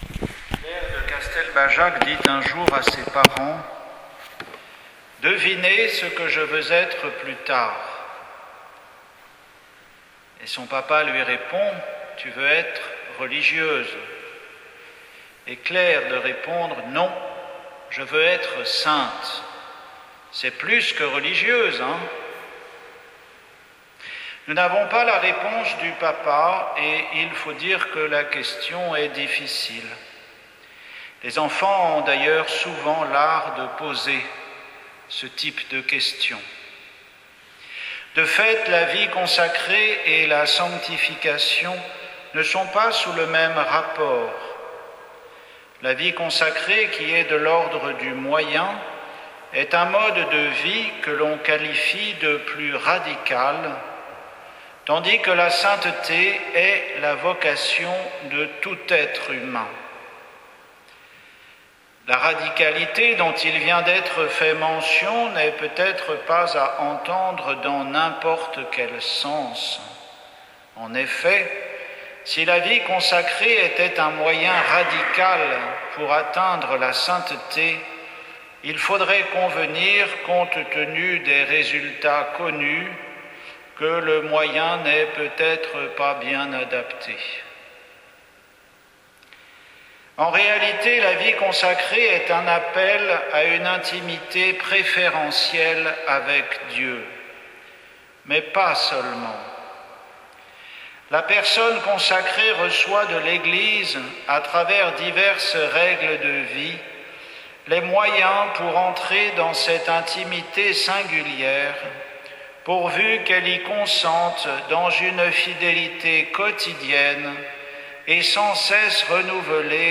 Homélie pour la Présentation du Seigneur, fête de la vie consacrée